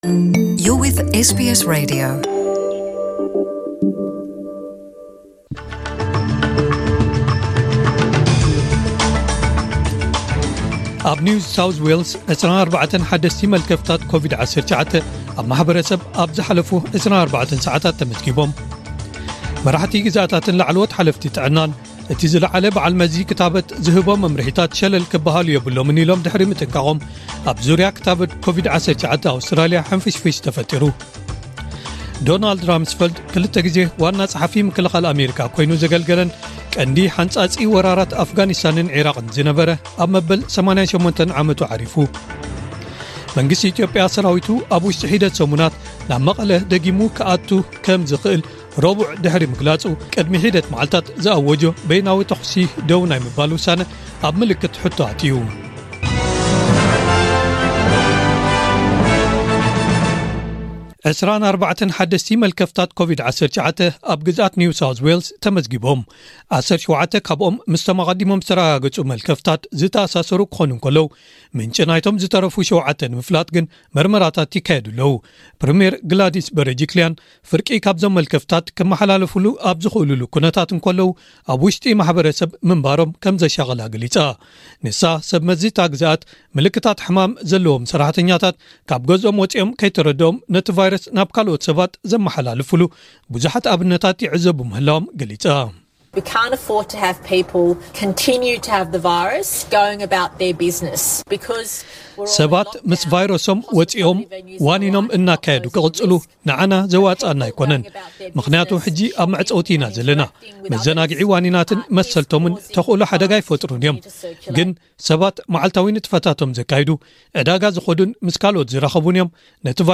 ዕለታዊ ዜና ኤስቢኤስ ትግርኛ (01/07/2021)